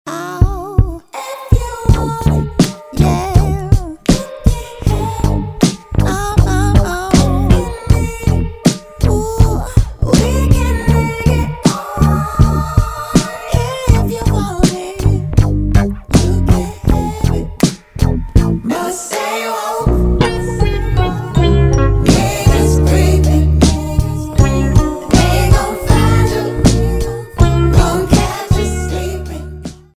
• Качество: 320, Stereo
спокойные
RnB
soul
из рекламы
psychedelic
Neo Soul